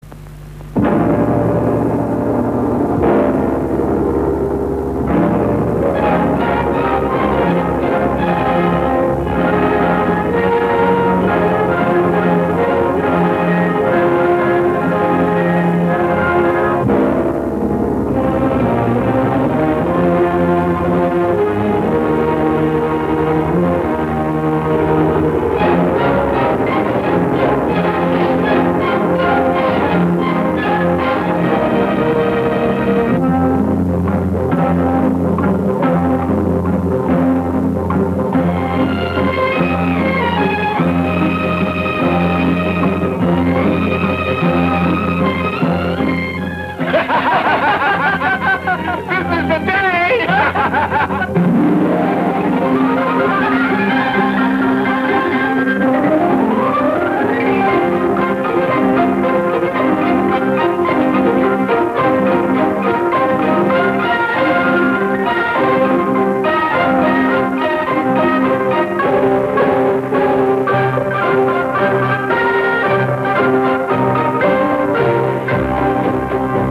the-good-earth-1937-movie-trailer-mgm-audiotrimmer-com.mp3